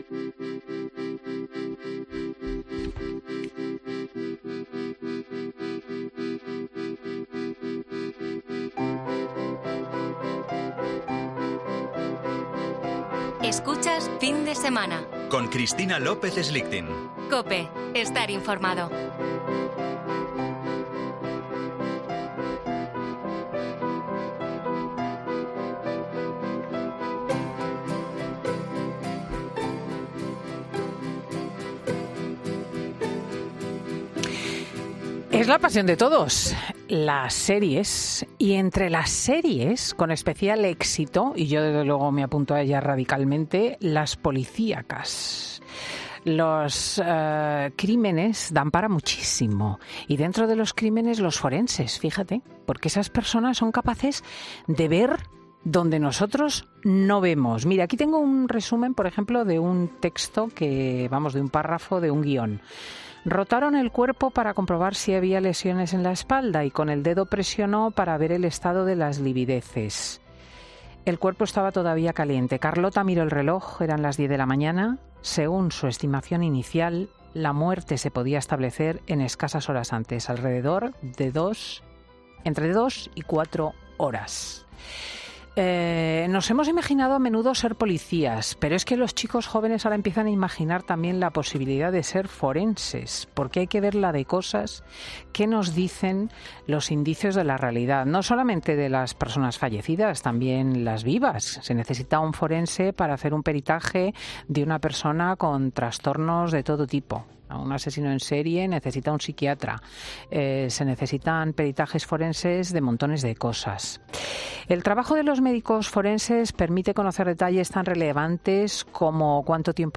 AUDIO: ¿Cómo es la relación que establece un médico forense con la víctima y sus familiares? Hablamos en fin de semana con una doctora que lo desvela...